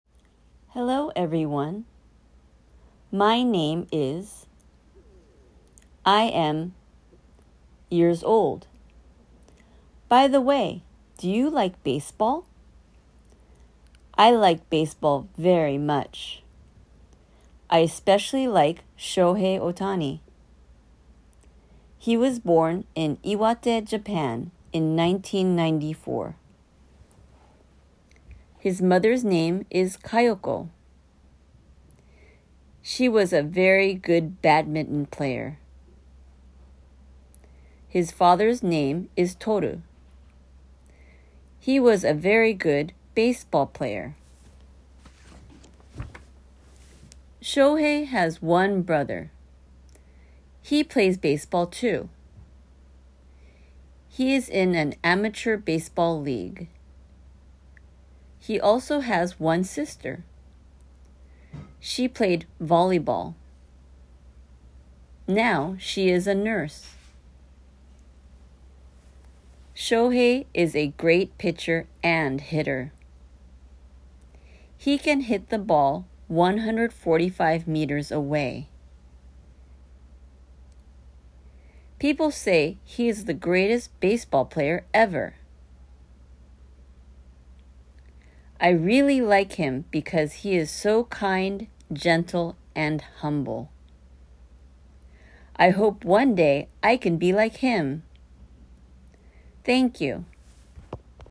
おてほん  (byネイティブ講師)
★女性の声バージョン